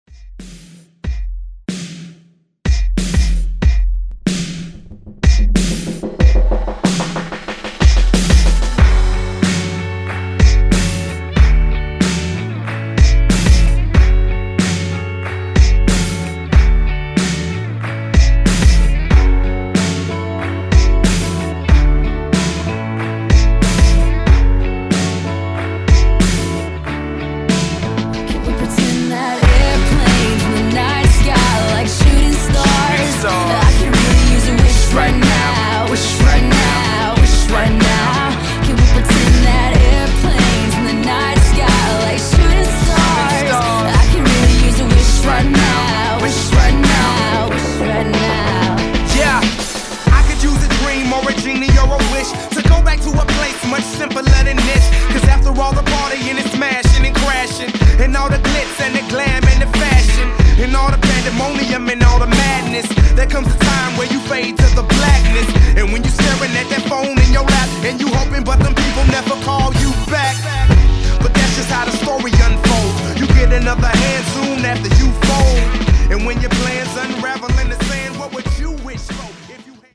Genres: 2000's , TOP40
Clean BPM: 120 Time